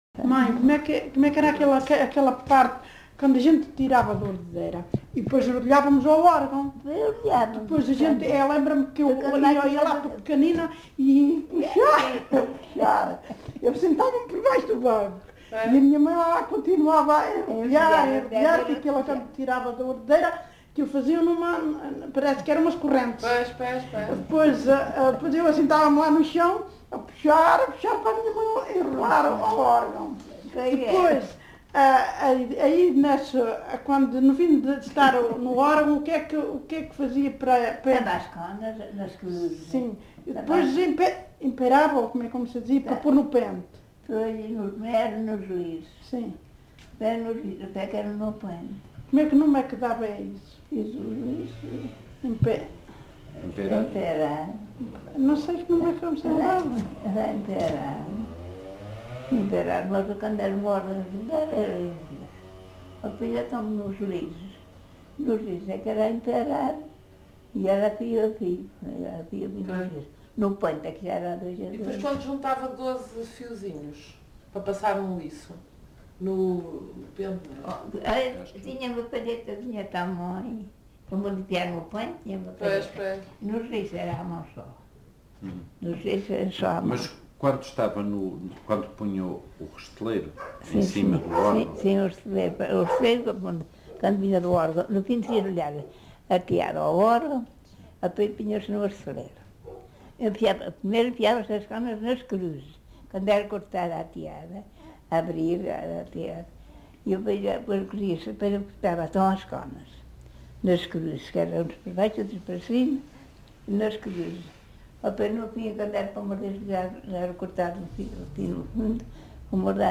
LocalidadePorto de Vacas (Pampilhosa da Serra, Coimbra)